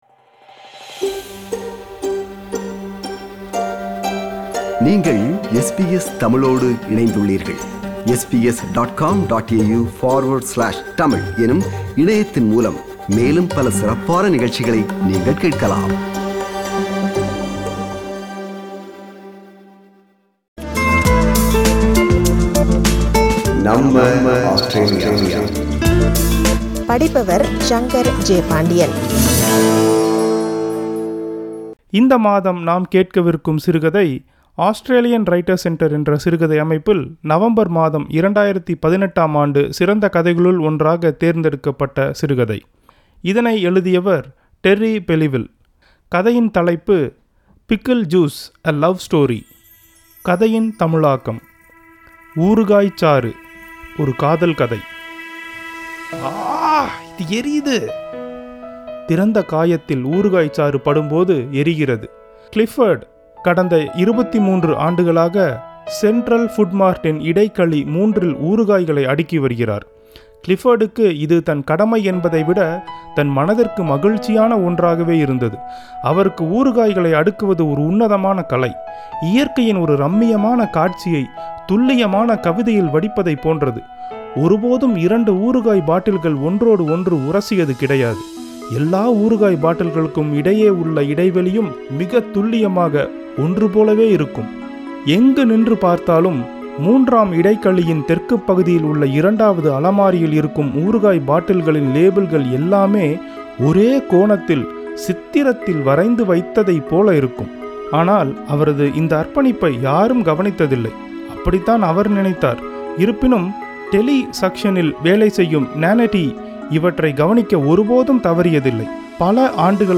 தமிழில் ஒரு ஆஸ்திரேலிய ஆங்கிலச் சிறுகதை – கதை 10